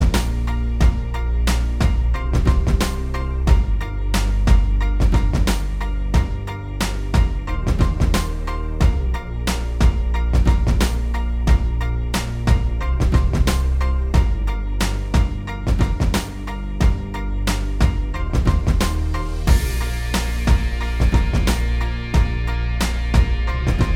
Minus Main Guitar Pop (2000s) 3:42 Buy £1.50